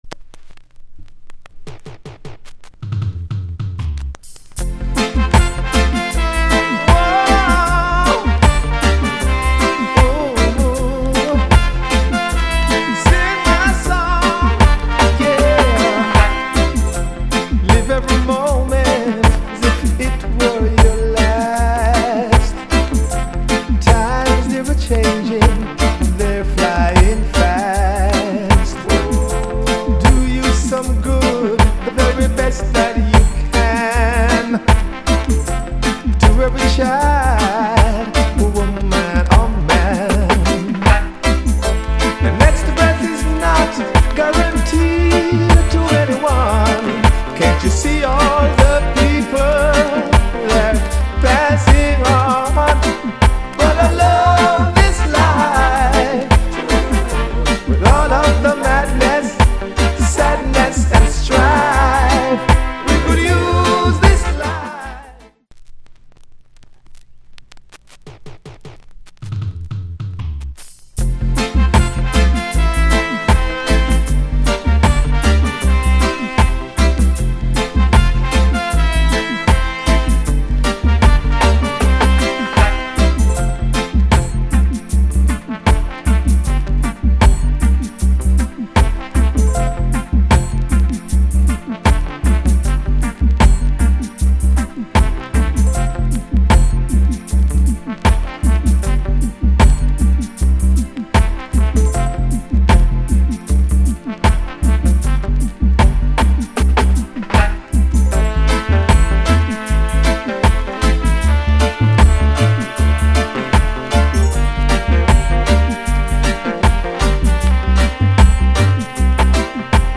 * '85 Good Vocal.